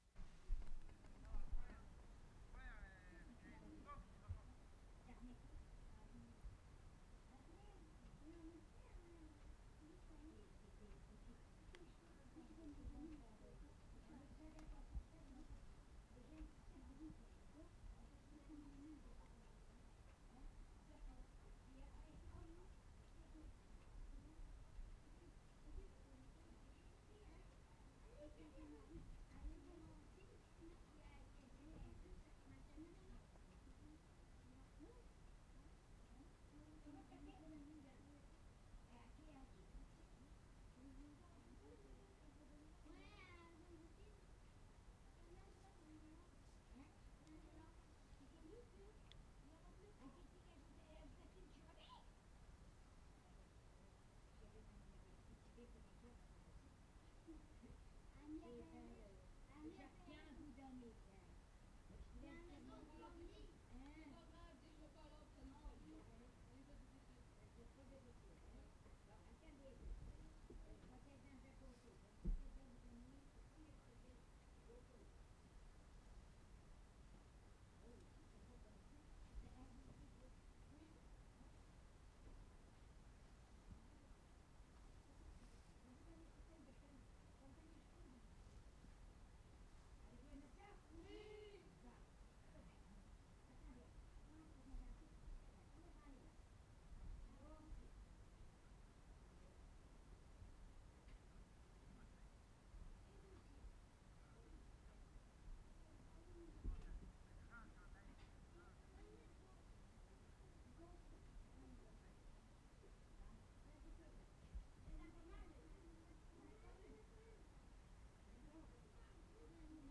WORLD SOUNDSCAPE PROJECT TAPE LIBRARY
ST. LAURENT - NORTH SHORE, QUEBEC Oct. 26, 1973
Hallowe'en 3'30"
13. Children inside house, muffled chatter, then coming outside (2'15"), running to next house, with candy boxes rattling, dog barking (3'05").